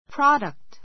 product 中 A2 prɑ́dəkt プ ラ ダ クト ｜ prɔ́dəkt プ ロ ダ クト 名詞 ❶ 生産物 , 産物 ; 製品 farm [factory] products farm [factory] products 農産物[工業製品] ❷ （努力などの） 成果, 結果 His success is a product of hard work.